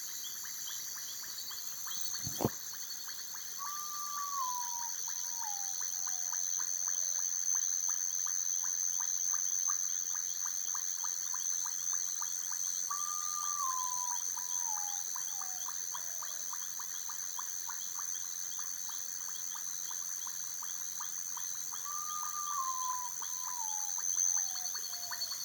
Common Potoo (Nyctibius griseus)
Country: Argentina
Province / Department: Córdoba
Condition: Wild
Certainty: Photographed, Recorded vocal